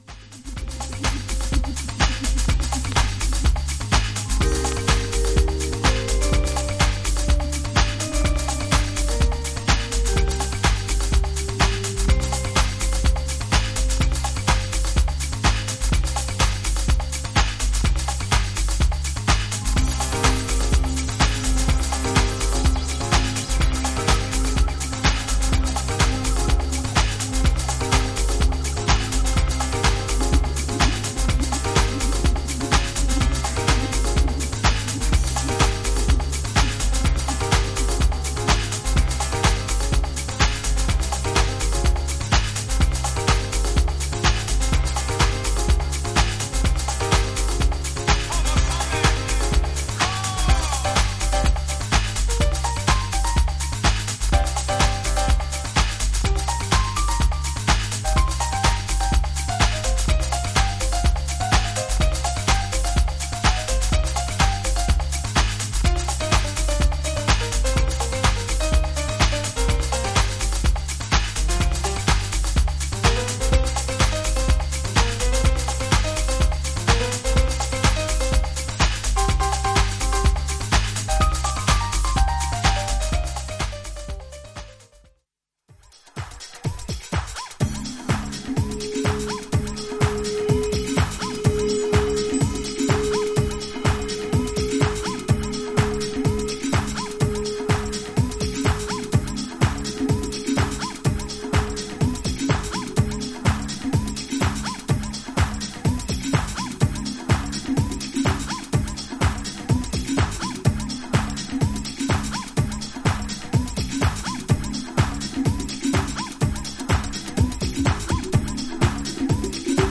パーカッシブなトラックにアンニュイでジャジーなコード感のシンセやエレピが絡む
バウンシーでファンキーな
浮遊感のあるパッド・シンセが揺らめくディープ・ハウス・ナンバー